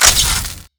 combat / weapons / damaged.wav
damaged.wav